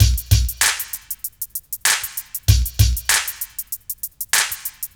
BD CLAP.wav